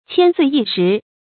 千歲一時 注音： ㄑㄧㄢ ㄙㄨㄟˋ ㄧ ㄕㄧˊ 讀音讀法： 意思解釋： 猶千載一時。